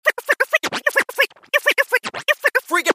Play, download and share Scratching 2 original sound button!!!!
scratching-2.mp3